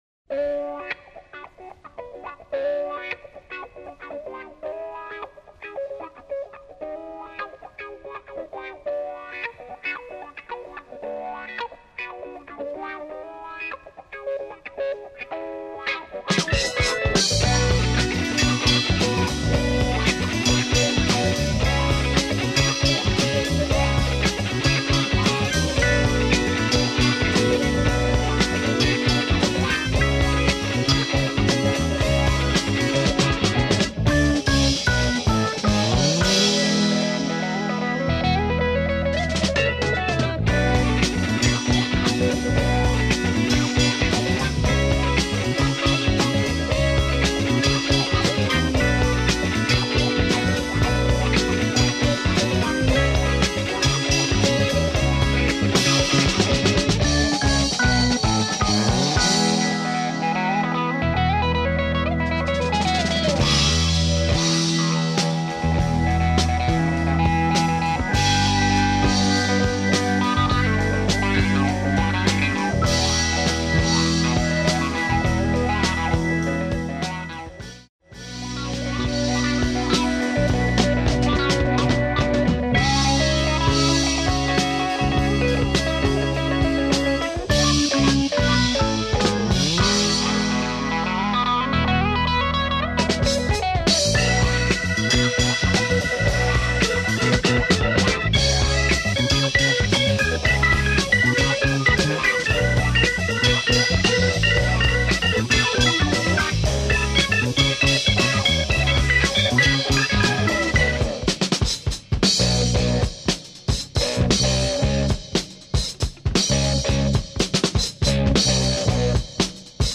British pop rock
a groovy pop tune with organ